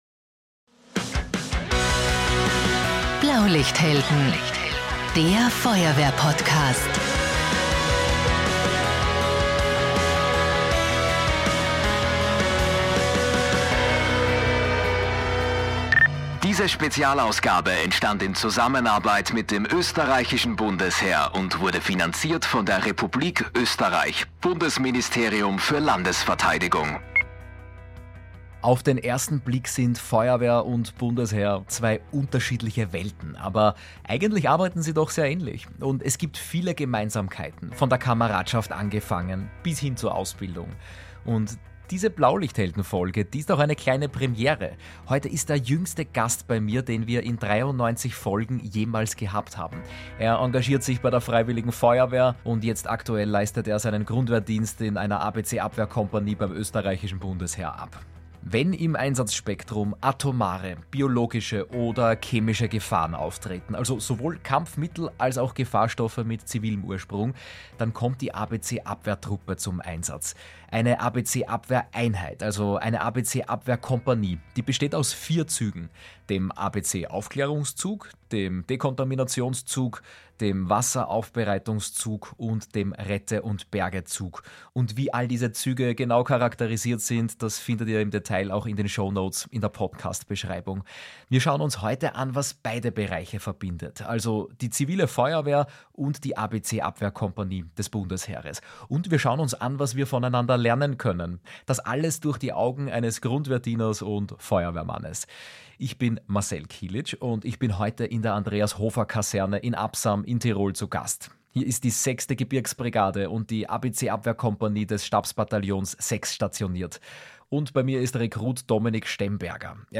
In dieser Folge melden wir uns „live“ von der großen Award-Show im Ö3-Haus am Wiener Küniglberg.